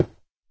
stone3.ogg